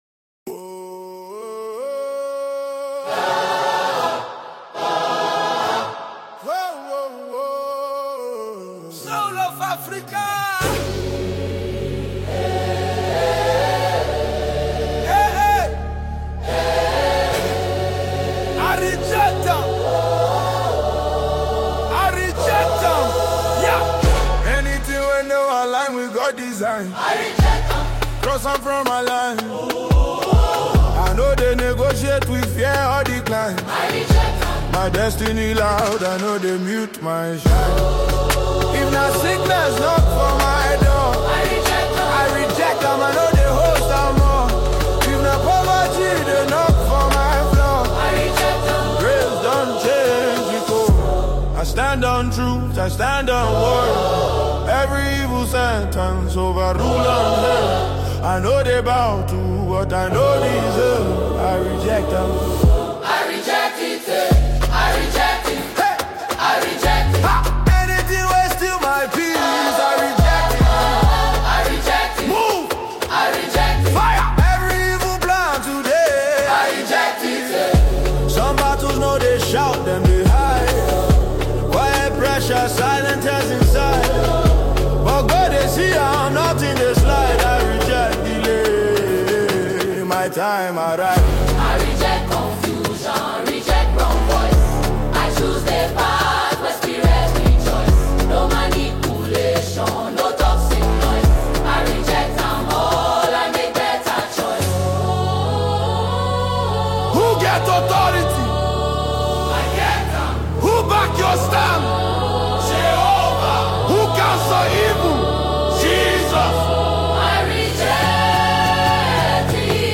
gospel declaration song